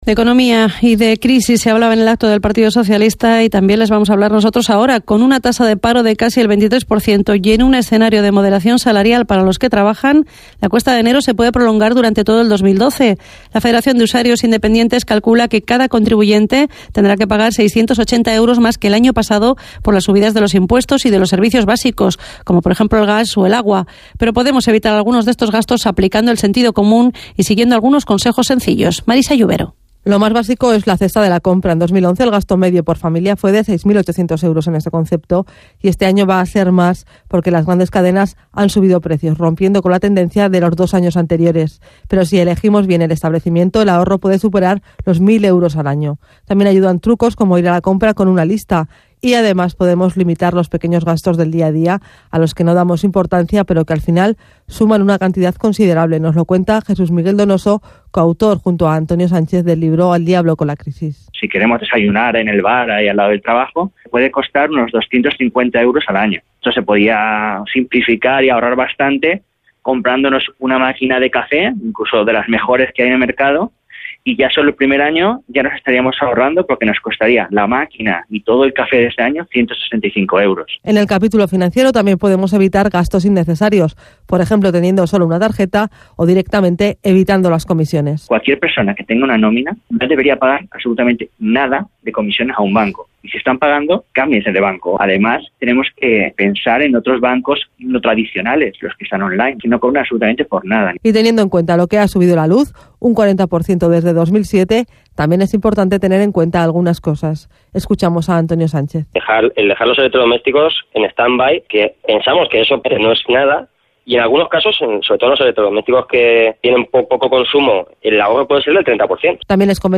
2012 Nuestro libro El 29 de Enero de 2012 en el informativo del mediodía de Aragón Radio incorporaron algunos cortes de una entrevista que nos hicieron esa misma semana.